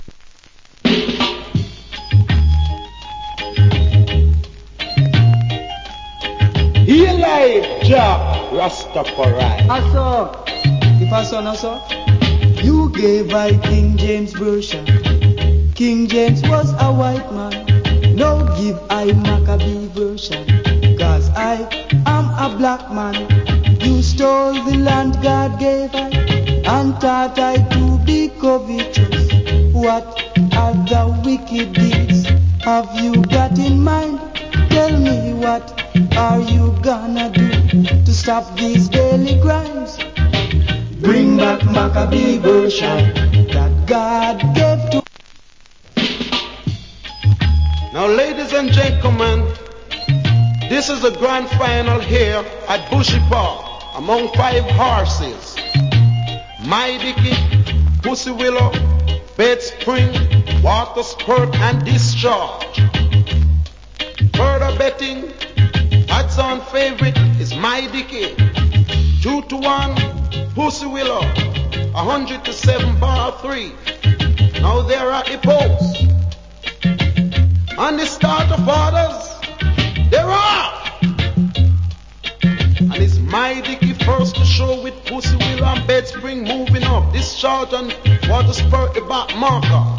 Nice Roots Reggae Vocal.